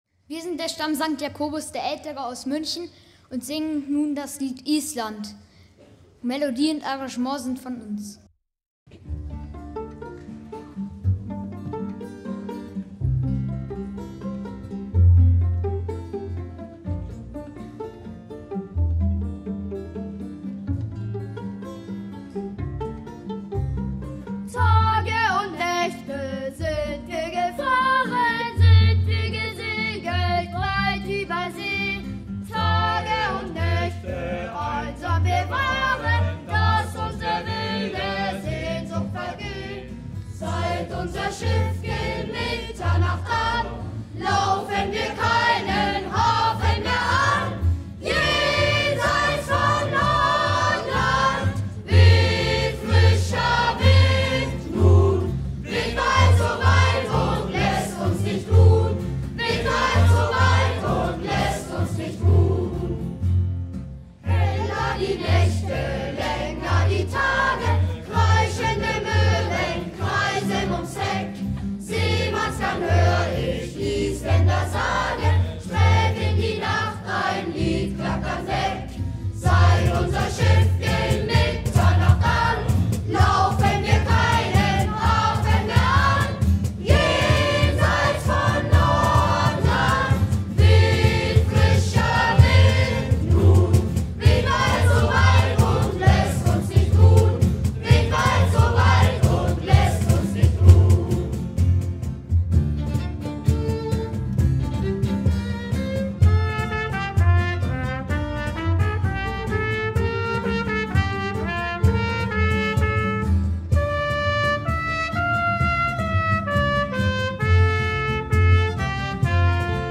Live
—> Neu-Ulmer Singewettstreit 2017